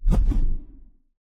somersalt_01.wav